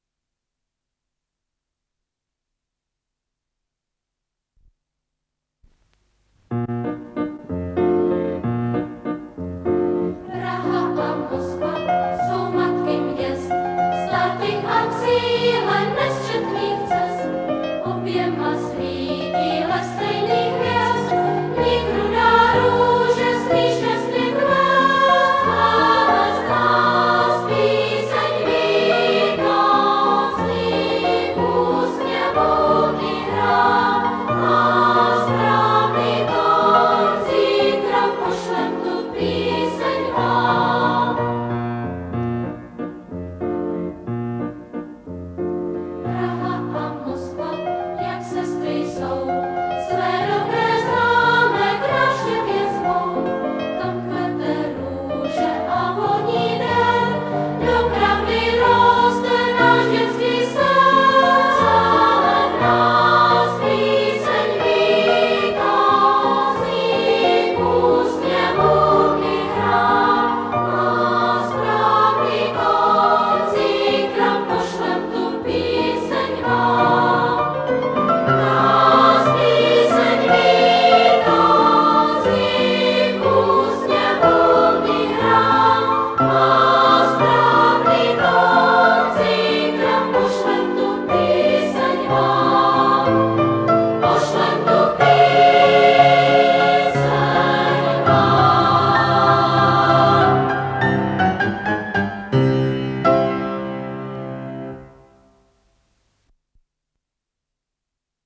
фортепиано
ВариантДубль моно